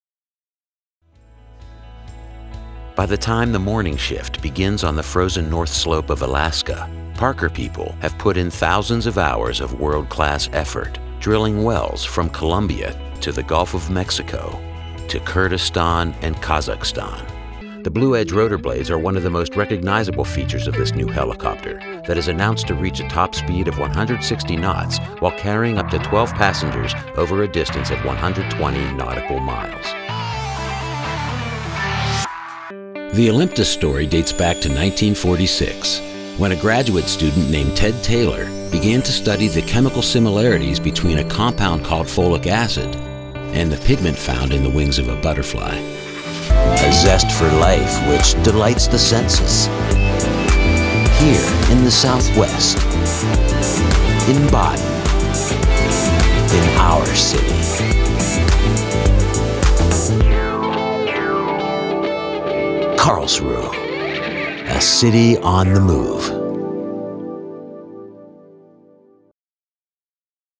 A voice like smooth gravel—buttery, bold and believable
Corporate
English - New York/East Coast
Middle Aged